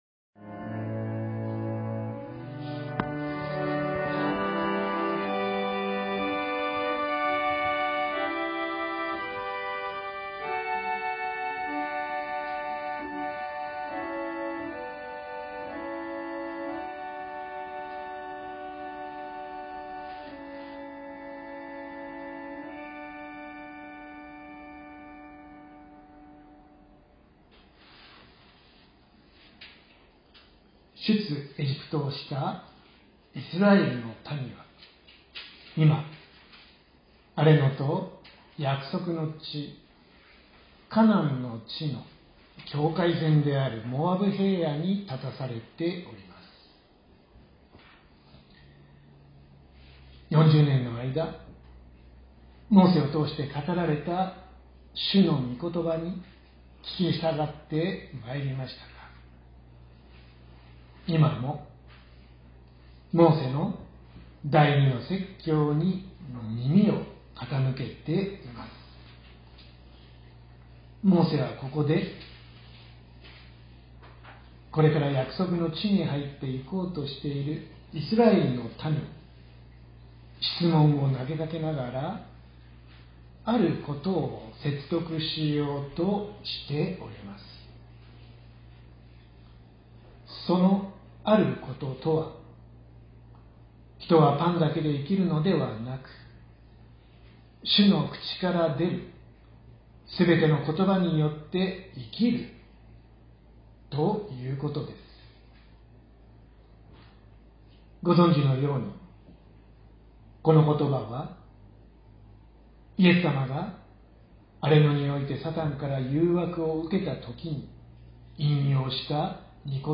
説教アーカイブ。
私たちは毎週日曜日10時20分から12時まで神様に祈りと感謝をささげる礼拝を開いています。